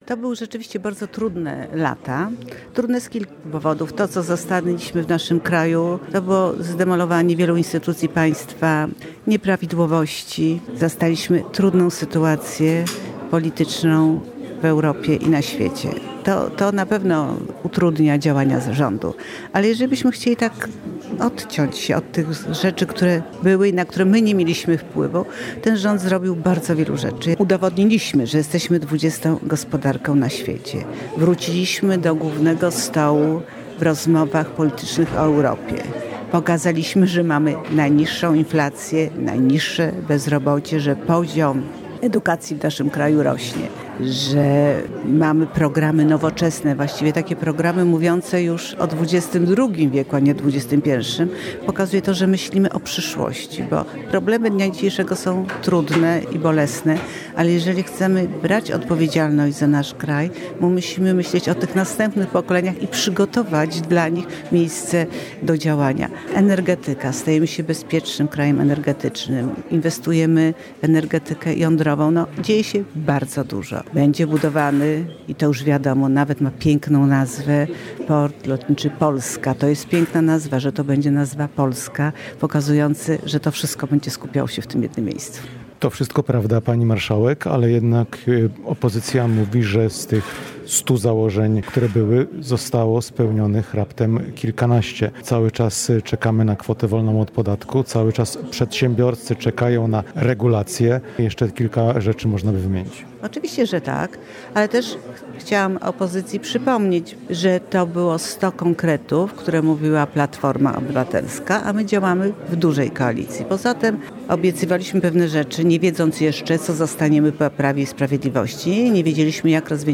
w specjalnej rozmowie dla Radia Lublin